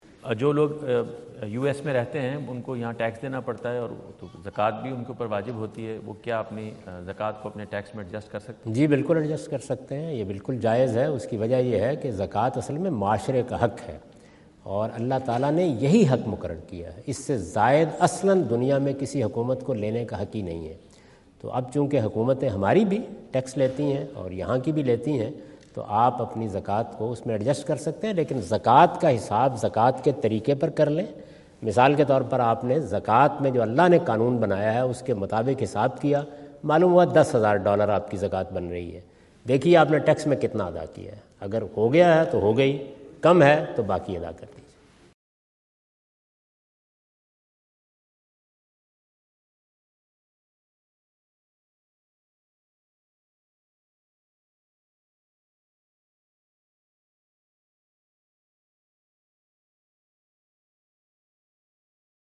Javed Ahmad Ghamidi answer the question about "Zakah and Taxes in Non-Muslim Countries" asked at North Brunswick High School, New Jersey on September 29,2017.
جاوید احمد غامدی اپنے دورہ امریکہ 2017 کے دوران نیوجرسی میں "غیر مسلم ممالک میں رہتے ہوئے زکوٰة اور ٹیکس ادا کرنا" سے متعلق ایک سوال کا جواب دے رہے ہیں۔